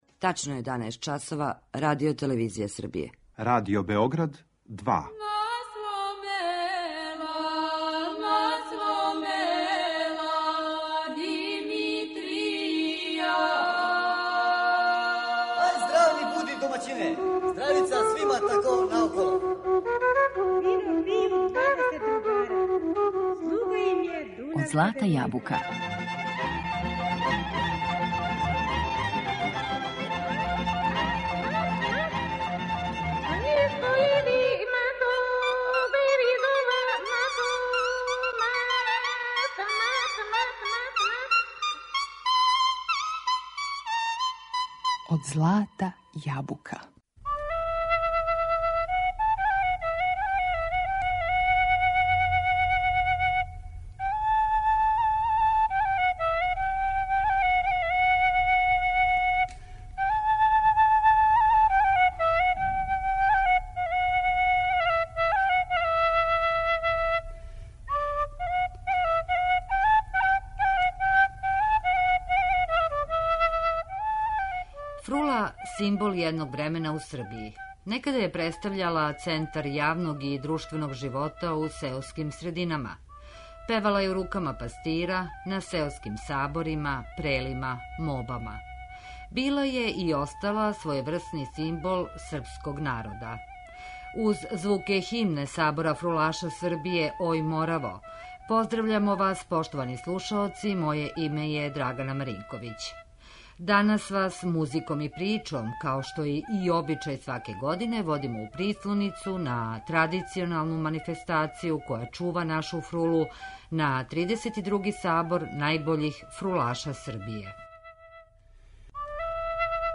Фрула
Данас у емисији Од злата јабука идемо у Прислоницу код Чачка, на традиционалну манифестацију која чува нашу фрулу, на 32. Сабор фрулаша Србије „Ој, Мораво". Представићемо победнике који су се такмичили прве вечери у изворној категорији.